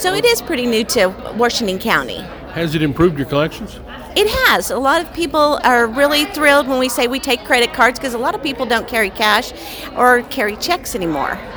Washington County Clerk Annette Smith was also a Guest Speaker at the Green Country Republican Women's Club luncheon Thursday. Making a payment at the Washington County Clerk's Office by credit card has been an option since since 2019.
Annette Smith, Guest Speaker, Washington County Clerk